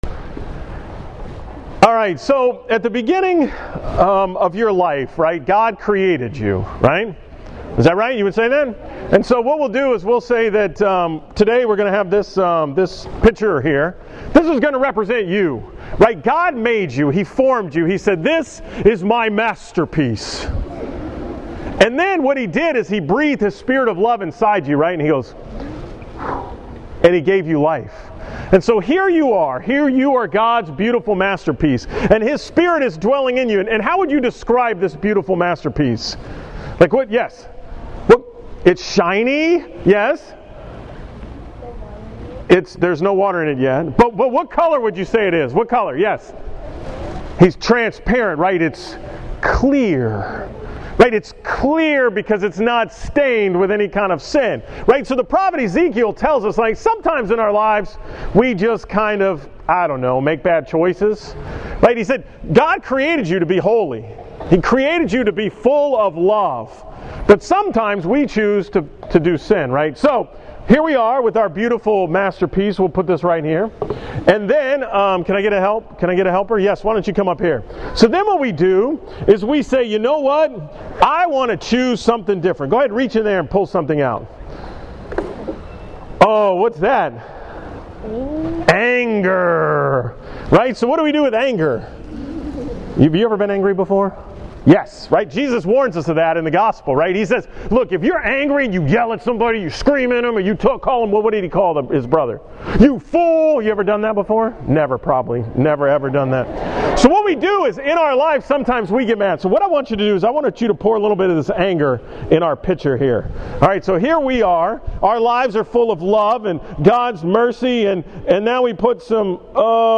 From the school Mass at John Paul II school on Friday, February 23